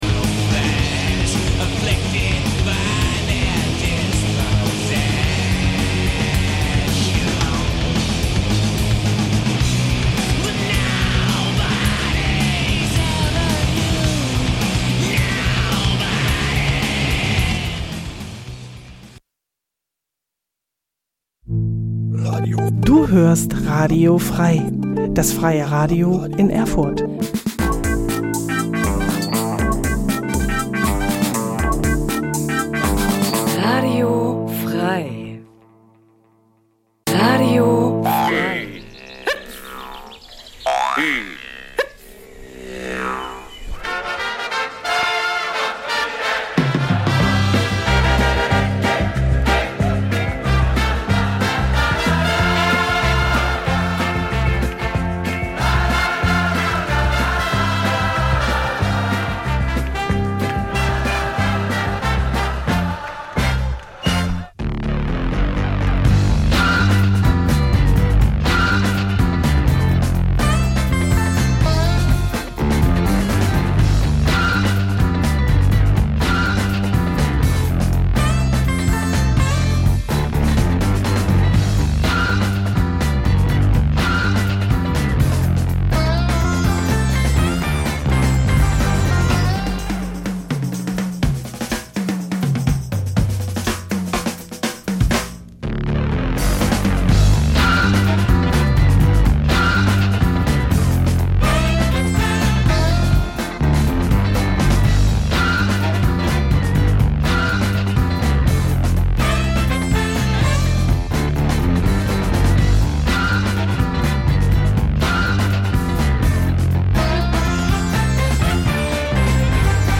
Trotzdem lassen wir es uns nicht nehmen immer wieder sonntags ein paar ganz besondere Perlen unserer Schallplattensammlung einer m�den aber durchaus interessierten H�rer-schaft zu pr�sentieren. Hierbei handelt es sich ausschlie�lich um leicht bek�mmliche Musikst�cke aus dem Be-reich Jazz, Soul, Funk, Soundtracks, Beat, French Pop u.s.w. Ganz nebenbei geben wir dem H�rer Informationen zu den gespielten Musikst�cken und vermitteln ihnen wertvolle Tips zu Bew�ltigung des